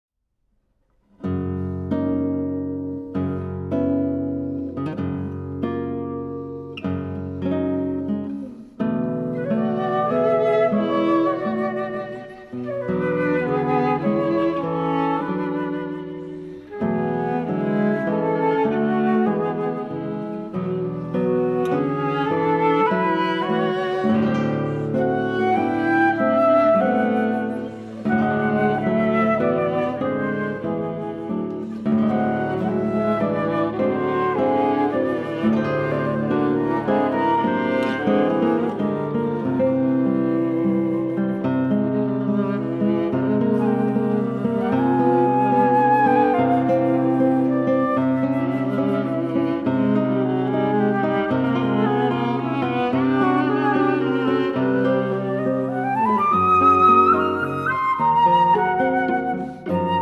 flute, viola and guitar